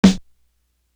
Definition Snare.wav